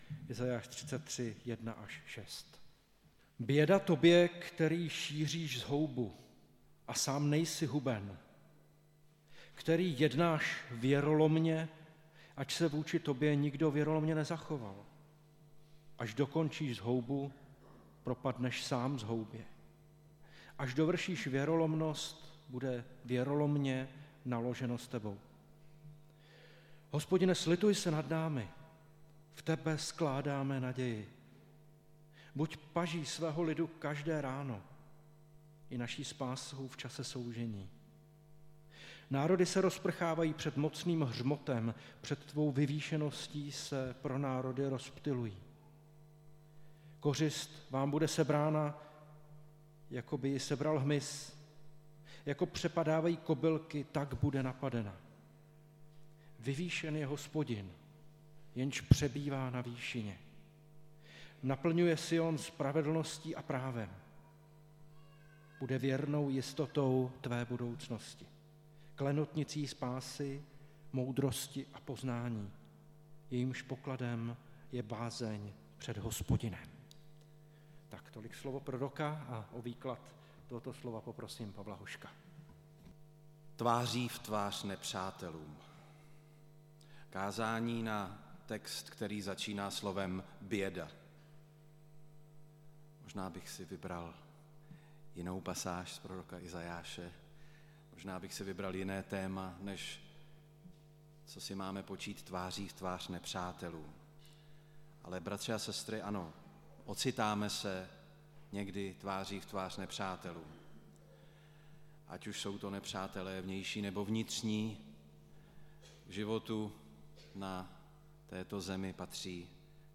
Nedělní kázání – 19.3.2023 Tváří v tvář nepřátelům